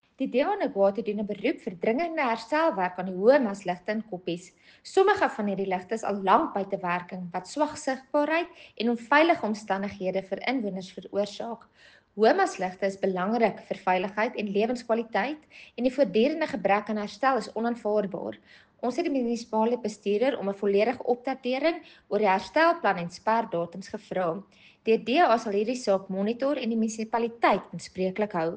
Afrikaans soundbites by Cllr Carina Serfontein and Sesotho soundbite by Cllr Kabelo Moreeng.